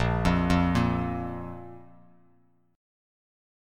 Bb5 Chord
Listen to Bb5 strummed